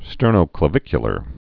(stûrnō-klə-vĭkyə-lər)